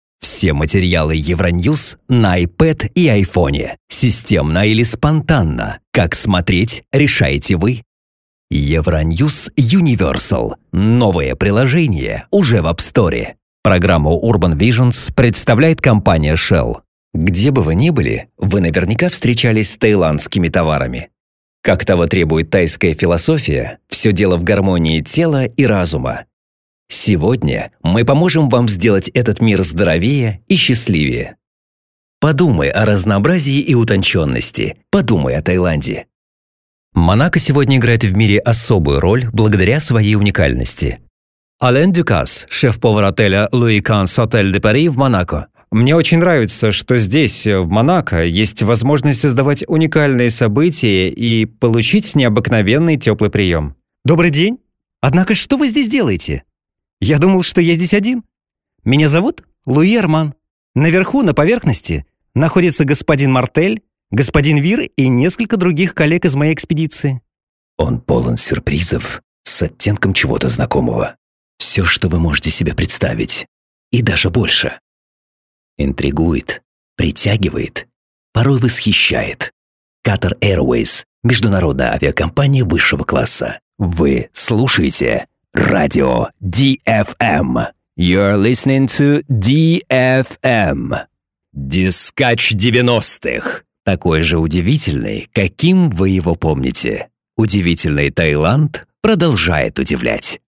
Тракт: rode, akg, shure, tlaudio, dbx